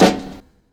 UIW_SNR.wav